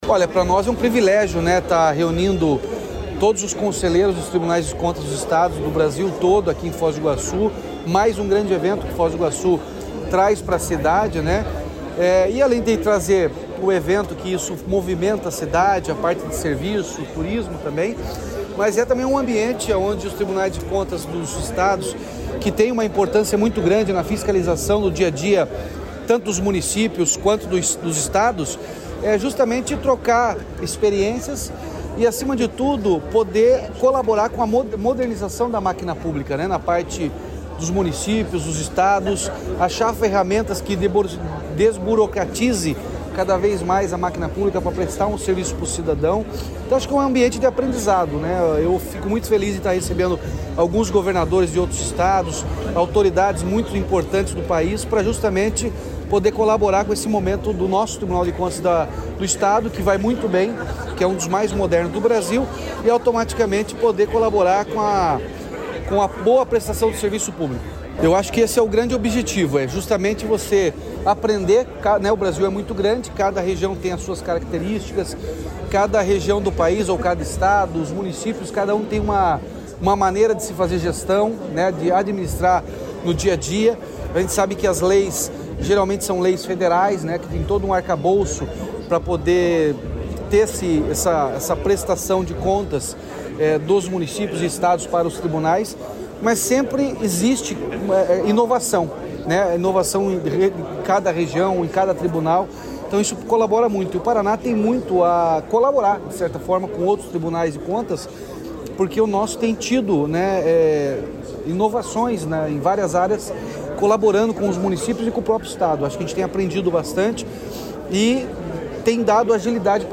Sonora do governador Ratinho Junior sobre a união dos órgãos de fiscalização
RATINHO JUNIOR - ENCONTRO NACIONAL TRIBUNAIS DE CONTAS.mp3